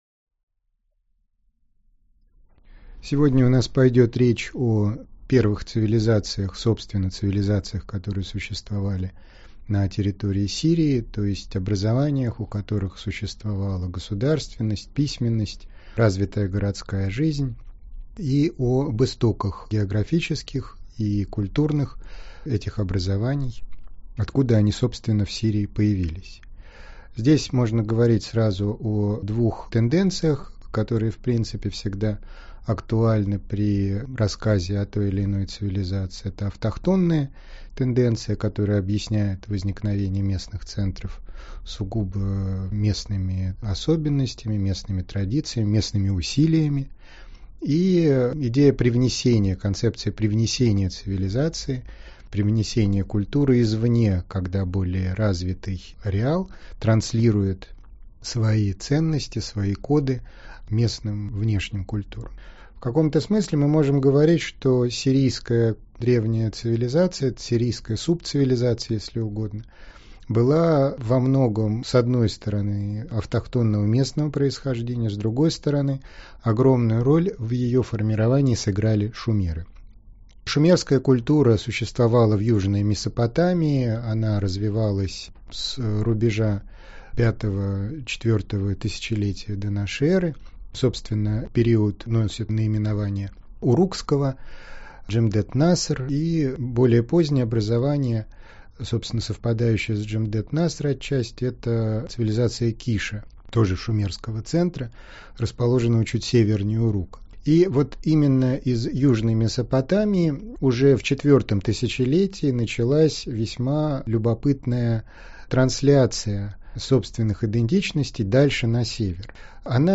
Аудиокнига Древние цивилизации на территории Сирии | Библиотека аудиокниг